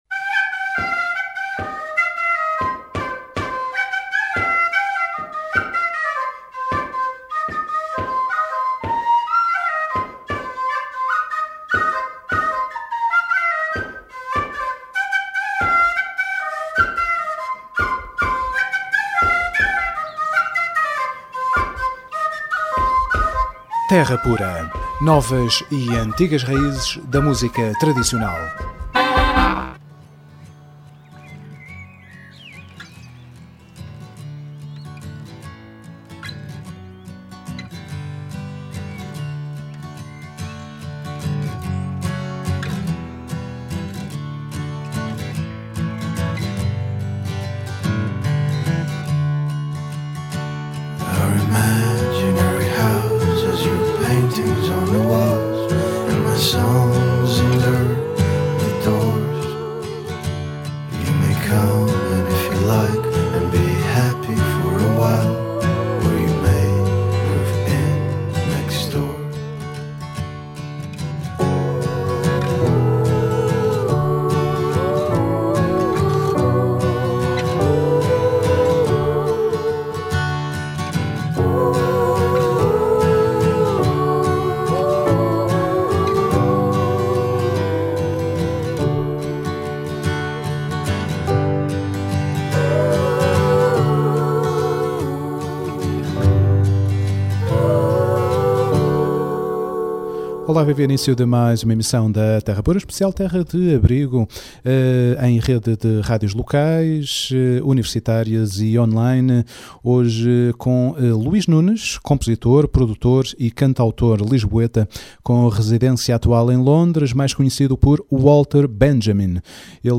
Terra Pura 16JUL12: Entrevista